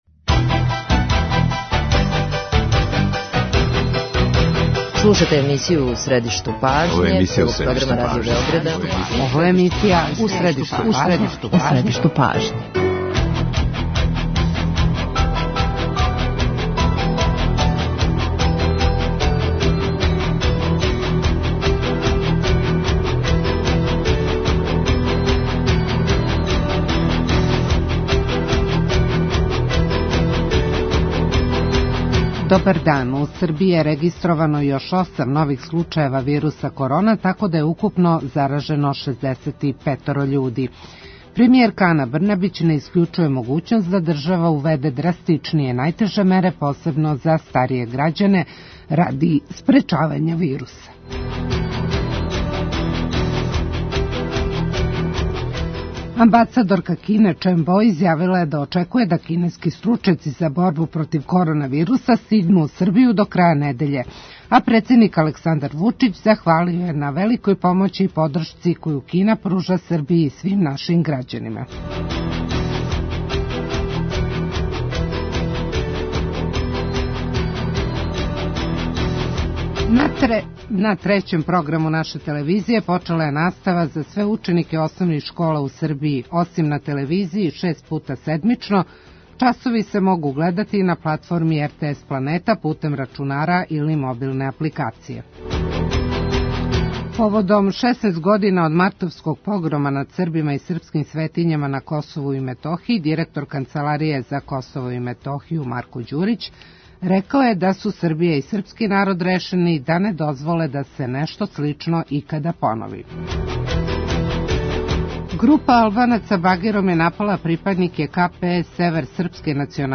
Од 18 часова преносимо конференцију за медије у Влади Србије.